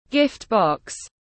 Hộp quà tiếng anh gọi là gift box, phiên âm tiếng anh đọc là /ˈɡɪft ˌbɒks/
Gift box /ˈɡɪft ˌbɒks/
gift-box.mp3